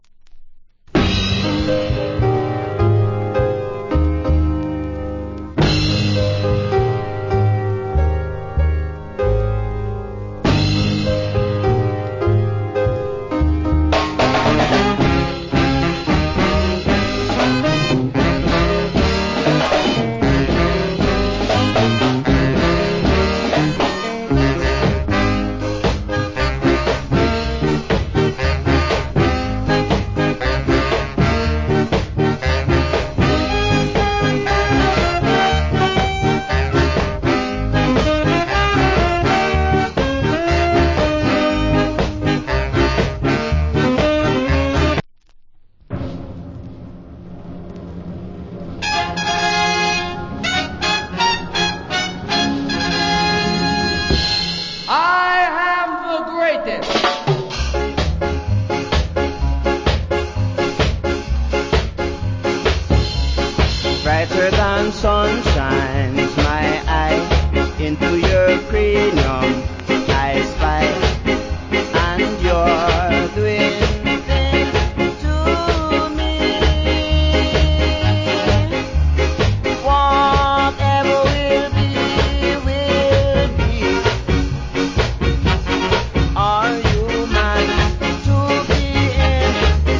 Nice Ska Inst.